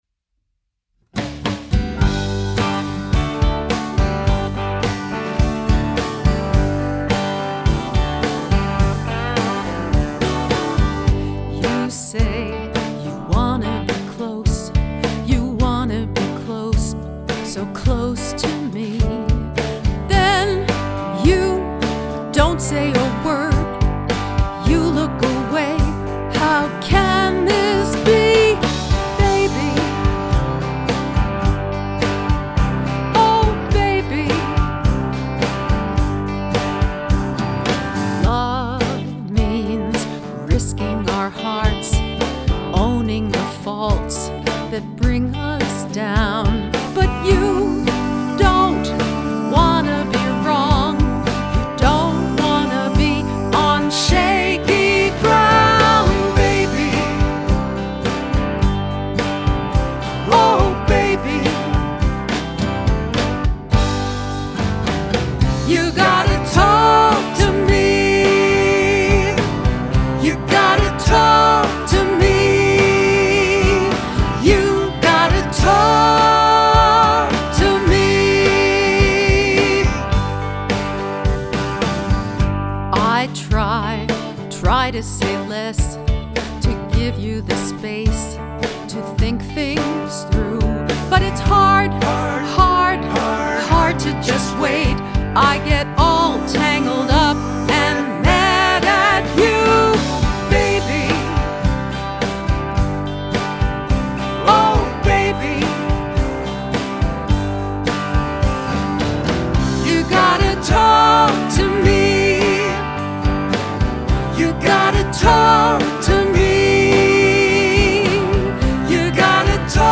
ya-gotta-talk-to-me-voc-egtr2-bgvocs.mp3